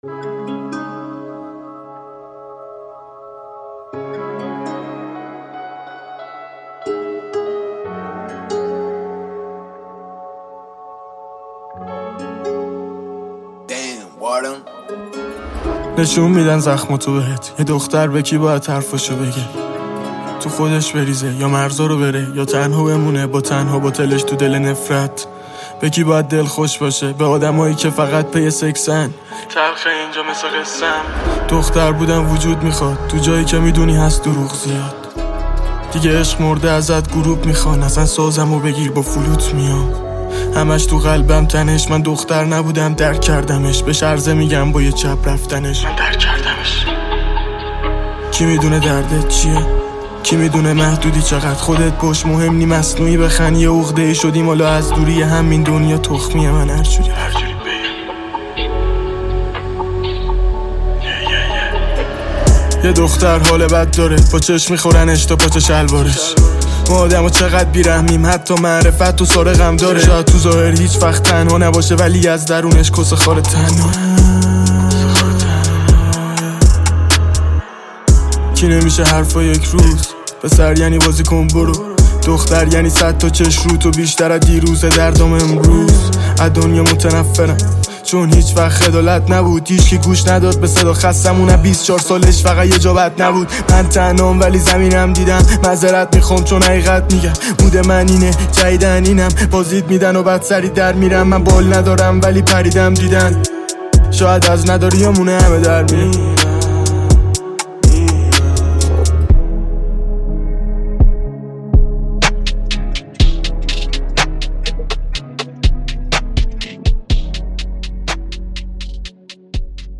دانلود آهنگ های جدید رپ فارسی های جدید